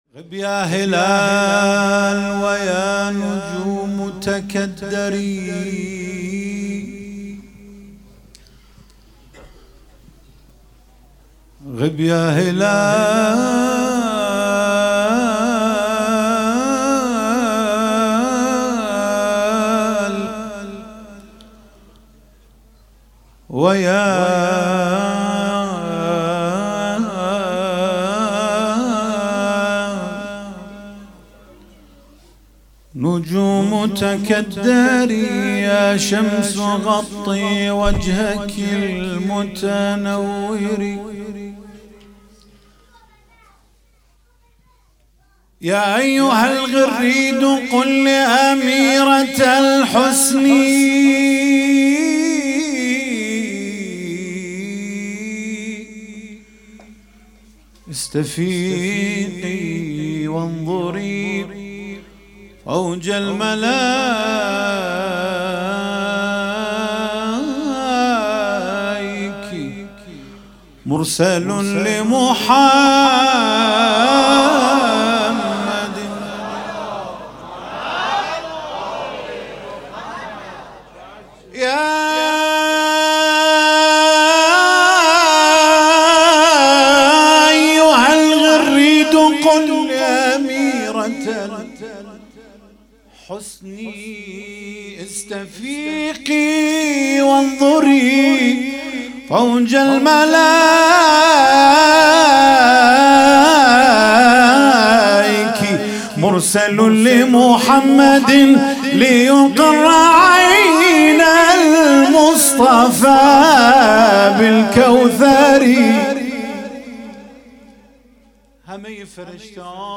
مولودی خوانی
ولادت حضرت زهرا (س) 97